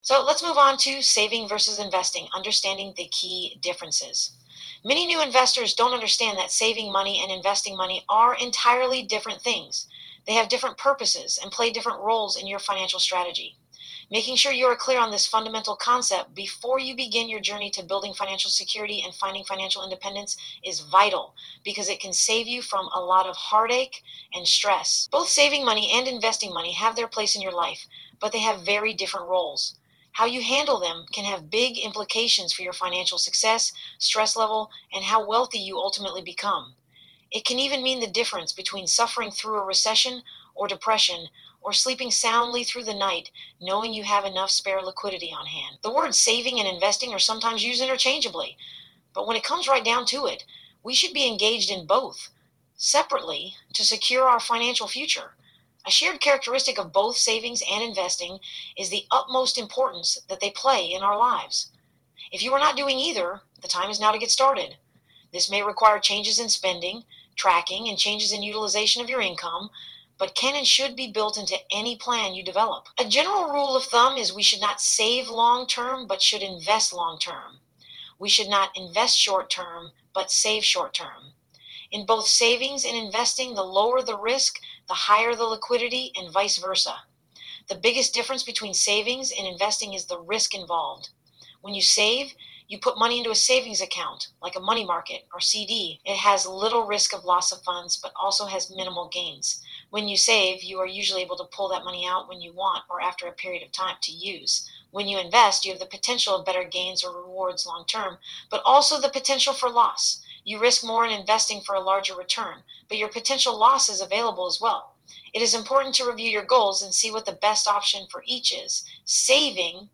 Listen to an Audiobook Excerpt Click to Listen get yours!